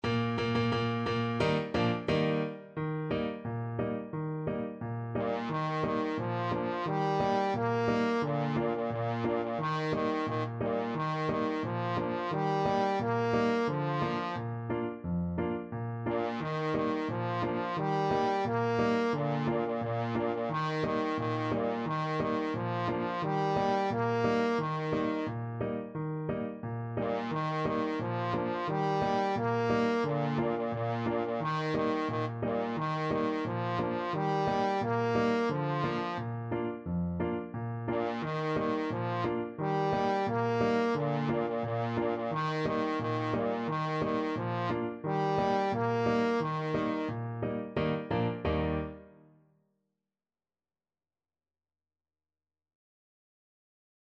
Trombone
Traditional Music of unknown author.
Eb major (Sounding Pitch) (View more Eb major Music for Trombone )
Steady march =c.88
Bb3-Bb4
2/4 (View more 2/4 Music)
Swiss